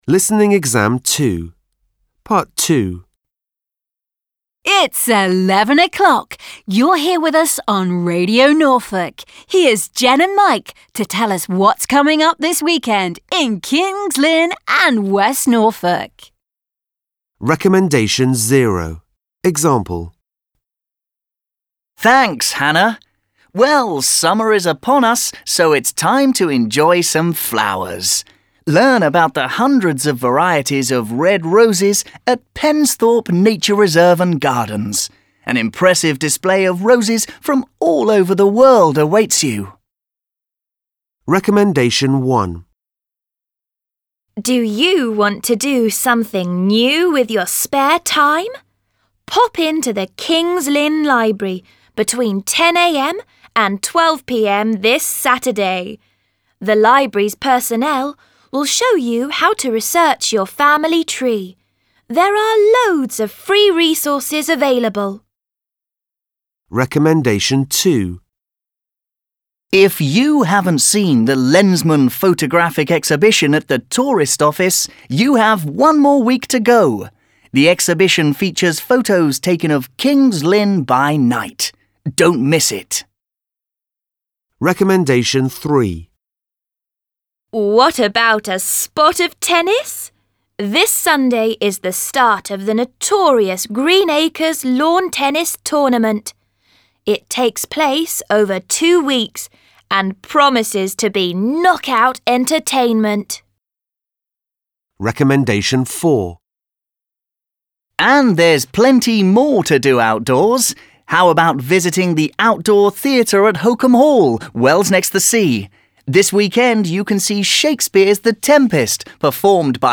You are going to listen to a local radio programme that